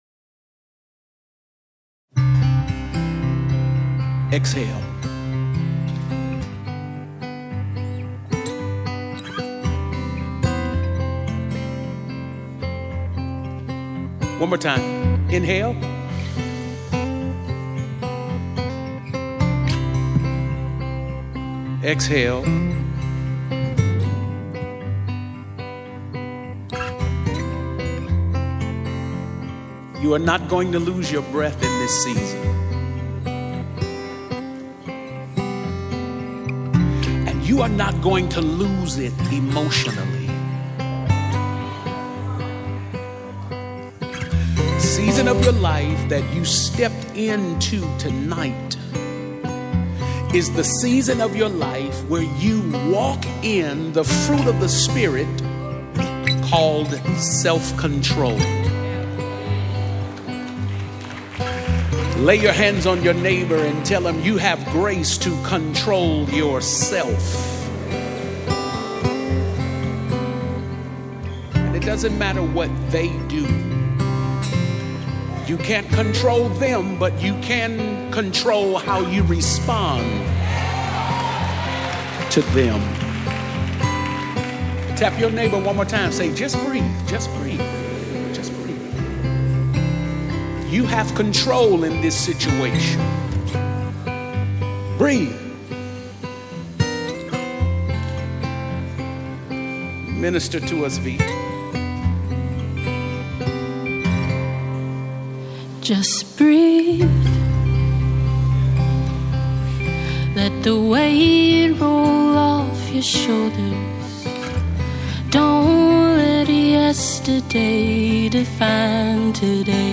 Contemporary songbird and spirit lifting lyricist